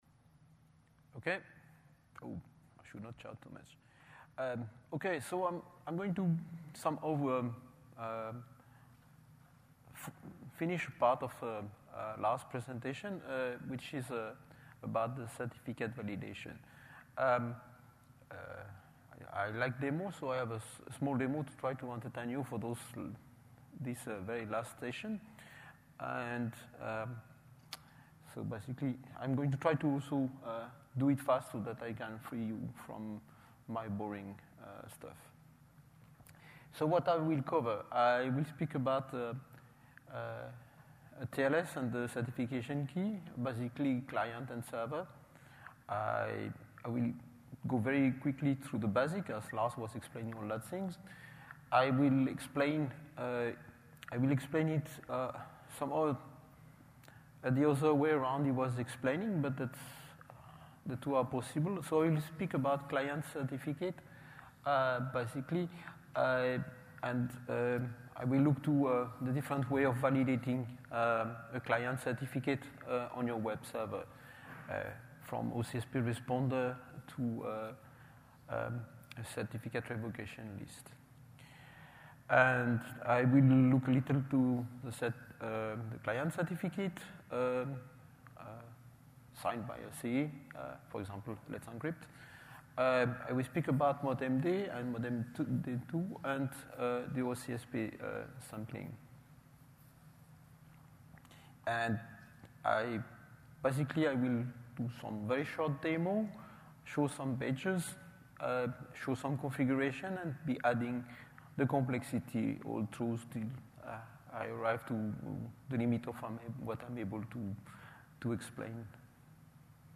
For the client certificates we look to OCSP and other validations. Demo and quick start example will provided during the talk.